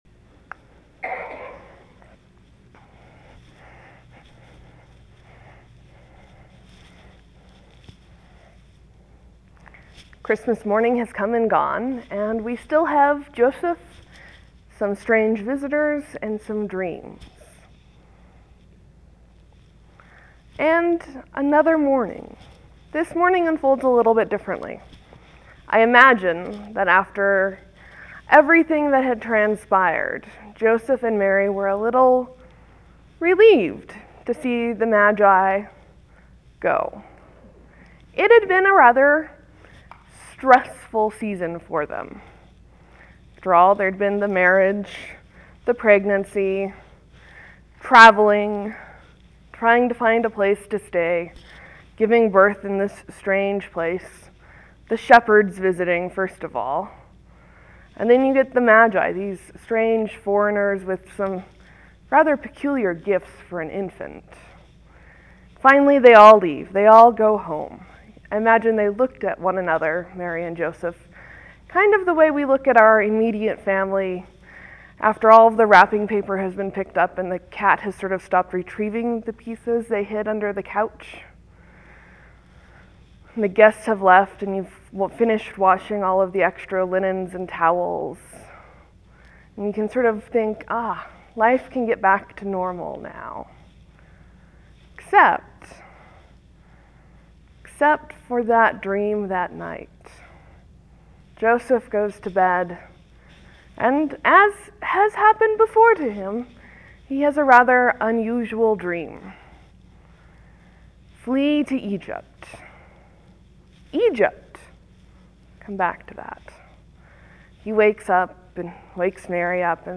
(There will be a few seconds of silence between when the recording starts and when the sermon starts. Thank you for your patience.)